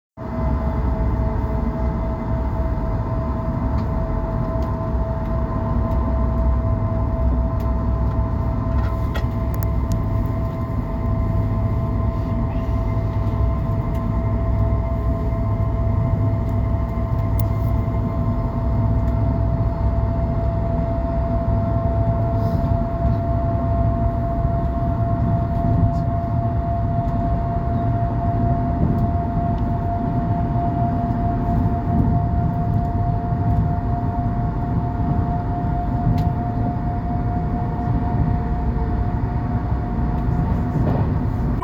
concrete